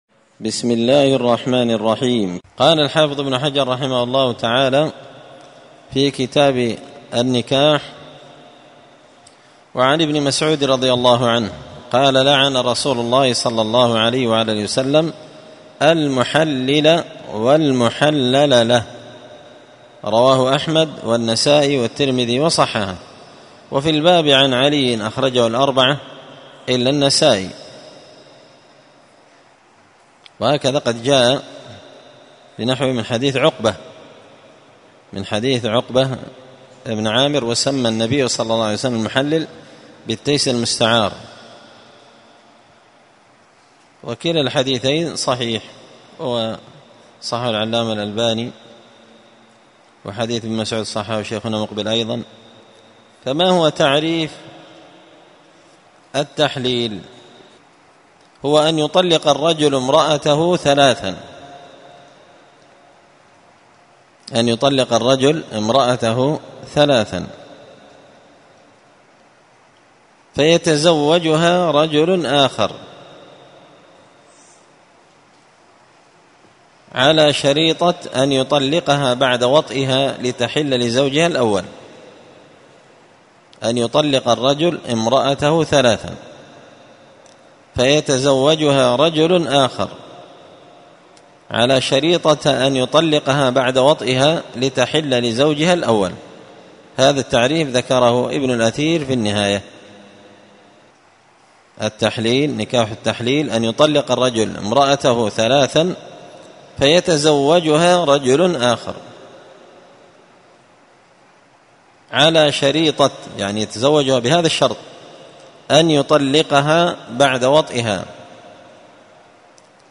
الدرس 14 تابع أحكام النكاح {نكاح التحليل}
مسجد الفرقان_قشن_المهرة_اليمن